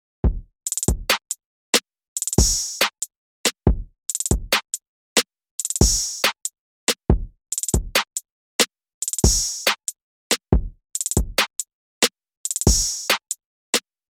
今回は、最初にダウンロードしたTrap向けキットSkyline Heatの「Tight Trap」を使うことにしました。
これが完成したドラムパターンです。
チキチキとハイハットが細かくなっているところは、「ノートリピート」でステップを分割して鳴らしています。
hiphop-gb-d.mp3